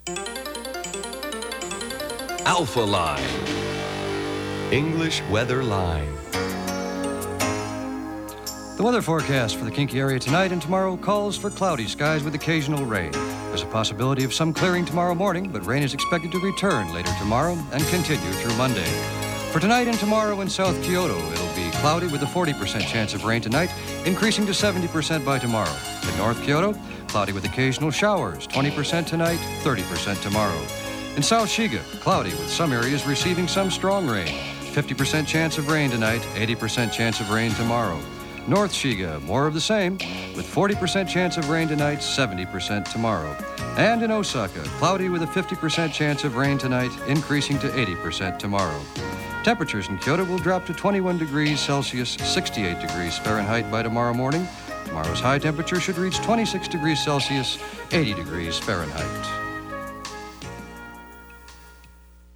受信機：SONY ステレオコンポ アナログチューナー型式不明
音源は全てステレオ録音です。